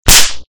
spank.ogg